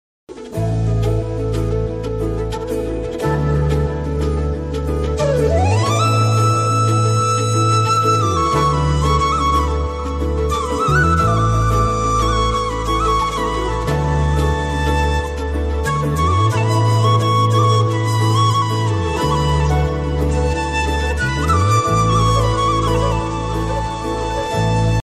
Best romantic background music ringtone for mobile.